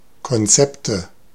Ääntäminen
Ääntäminen Tuntematon aksentti: IPA: /kɔnˈtsɛptə/ Haettu sana löytyi näillä lähdekielillä: saksa Käännöksiä ei löytynyt valitulle kohdekielelle. Konzepte on sanan Konzept monikko.